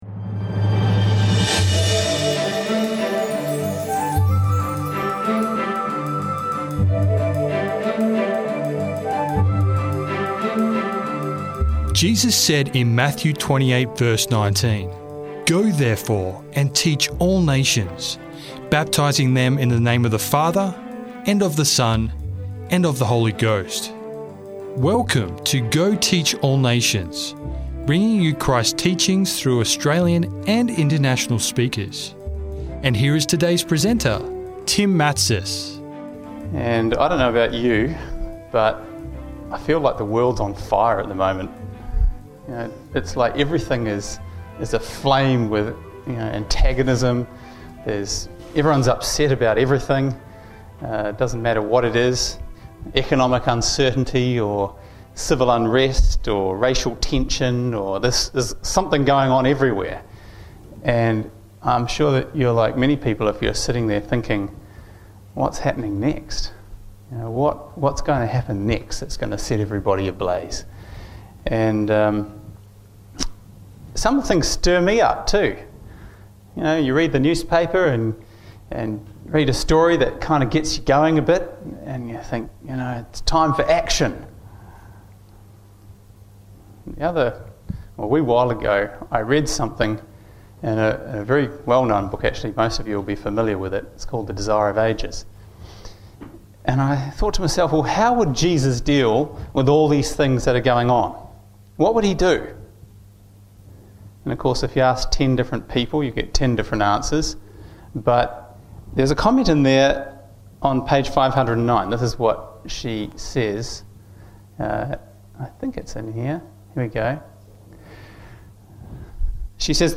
Sermon Audio: Go Teach All Nations